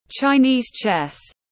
Môn cờ tướng tiếng anh gọi là chinese chess, phiên âm tiếng anh đọc là /tʃaɪˈniːz tʃes/ .
Chinese chess /tʃaɪˈniːz tʃes/